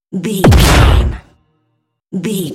Dramatic hit laser shot
Sound Effects
Atonal
heavy
intense
dark
aggressive